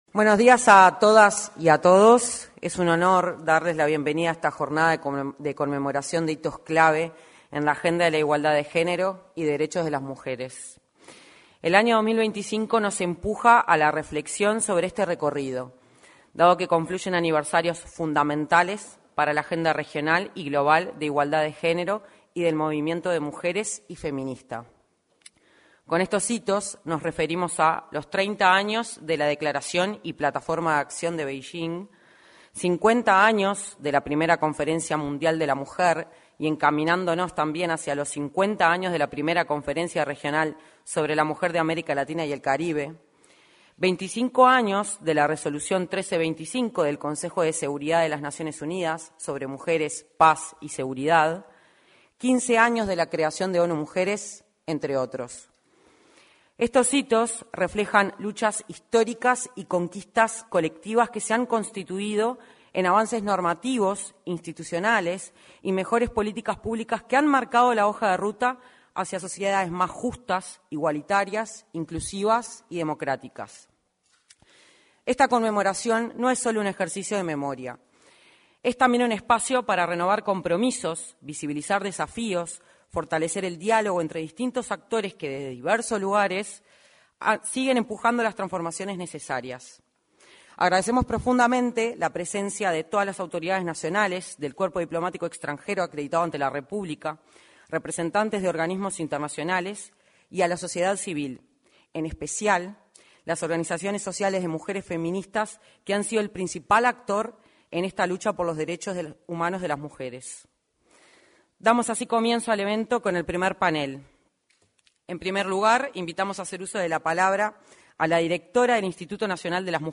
Conmemoración del 30.° aniversario de la Declaración de Beijing 29/07/2025 Compartir Facebook X Copiar enlace WhatsApp LinkedIn En el auditorio del anexo a la Torre Ejecutiva, organismos estatales celebraron el 30.° aniversario de la Declaración y la Plataforma de Acción de Beijing. En la oportunidad, se expresaron la directora del Instituto Nacional de las Mujeres, Mónica Xavier, y la subsecretaria de Relaciones Exteriores, Valeria Csukasi.